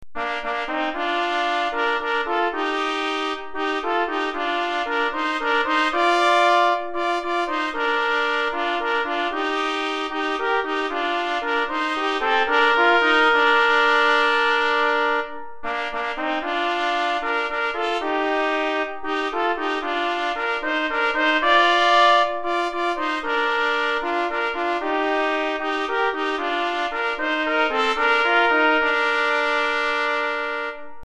Recueil pour Trompette ou cornet - 2 Trompettes